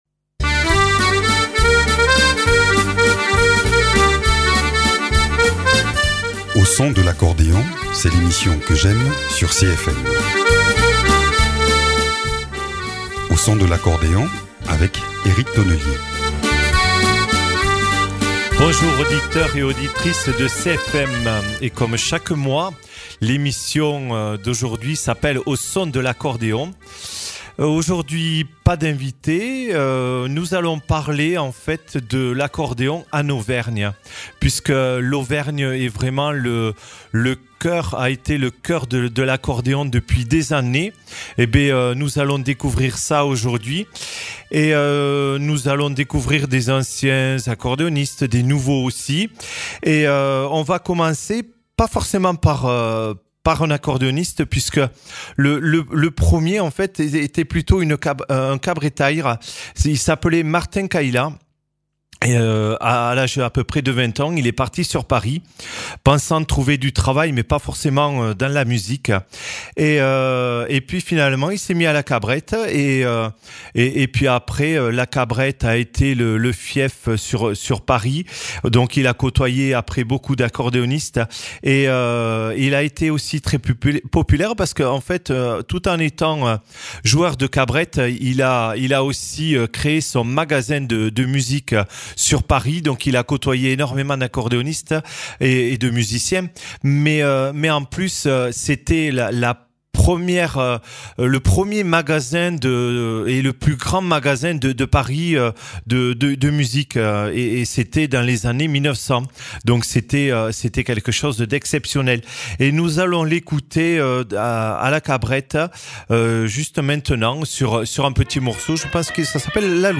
Nous parcourons aujourd’hui en musique une région qui aime l’accordéon, les bals et thés dansants et où l’on retrouve aussi de grands noms dans ce domaine.